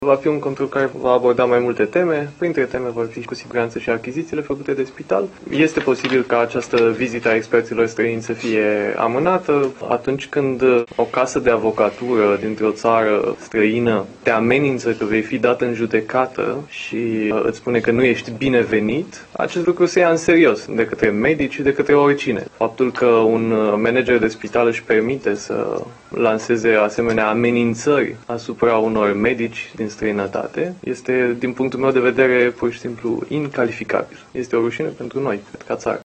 Ministrul Sănătății, Vlad Voiculescu spune că experții străini vor veni în control, cu altă ocazie:
06dec-13-Voiculescu-control-la-Sfanta-Maria.mp3